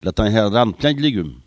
Maraîchin
Patois
Locution